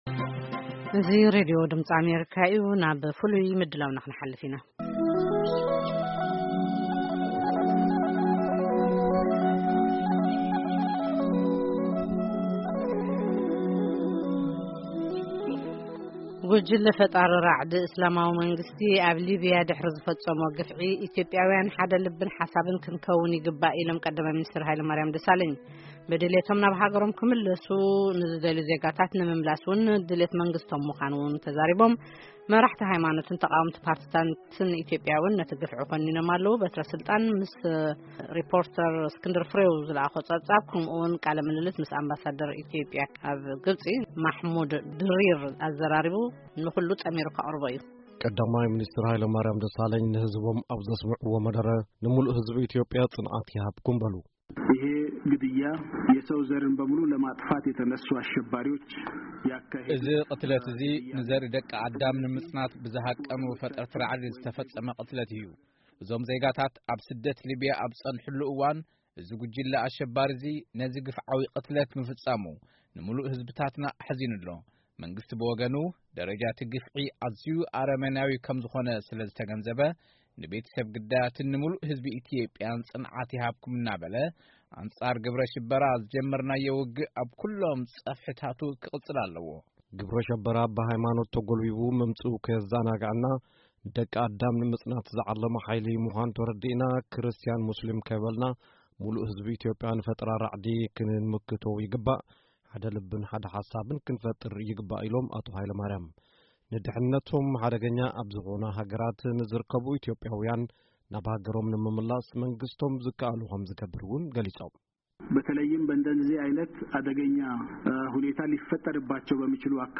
ዝተዋደደ ሪፖርት ብዛዕባ'ቲ አብ ሊብያ ዝተፈፀመ ቅትለት ኢትዮጵያዊያን